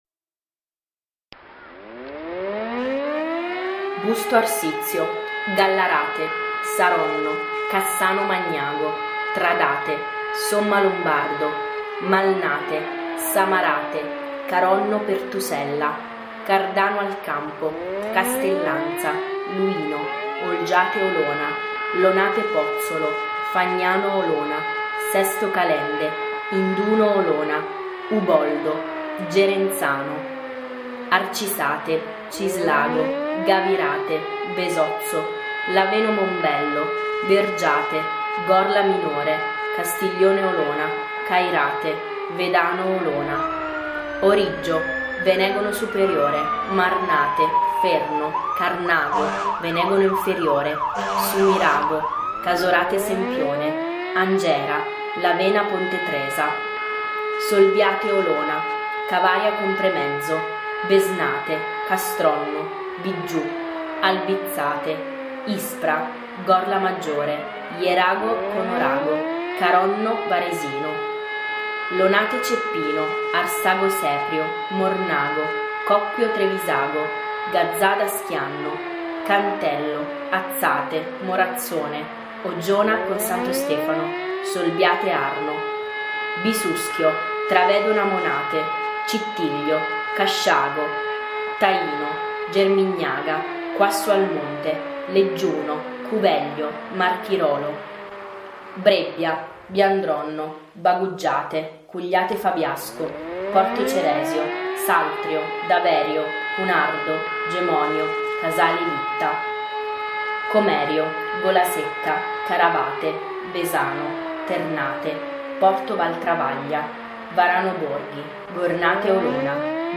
Op.1 per voce e sirene